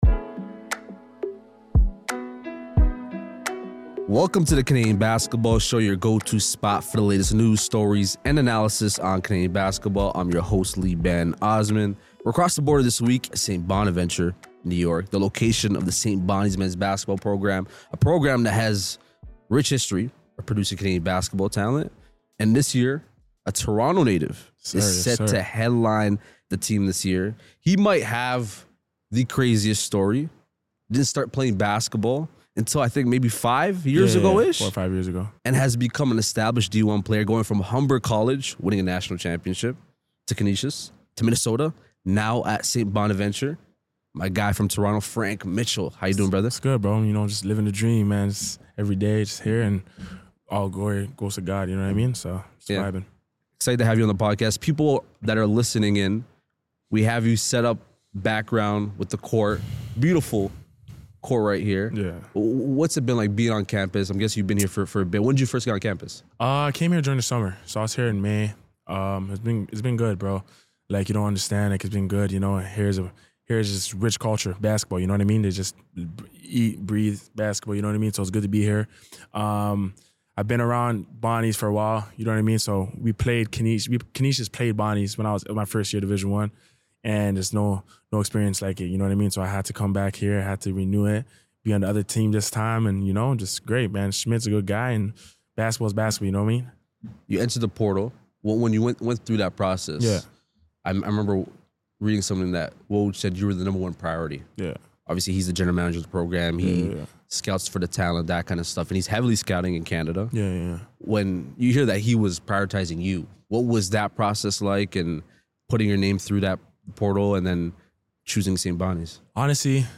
exclusive sit-down interview